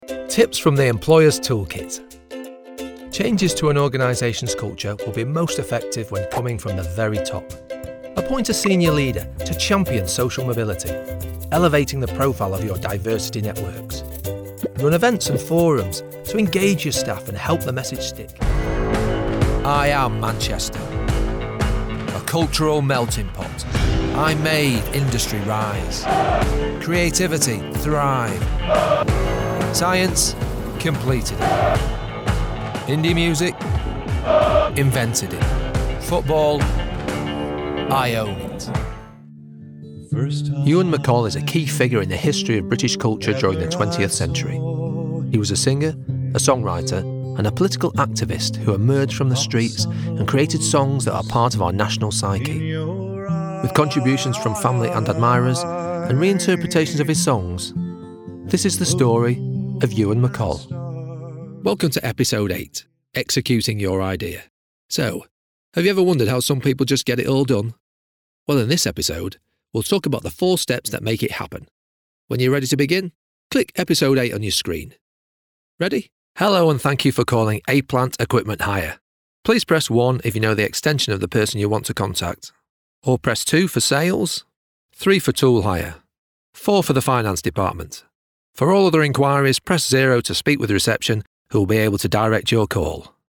Download Commercial Voicereel
My voice is friendly, relaxed, and naturally conversational — perfect for commercials, explainers, animations, corporate content, and promos.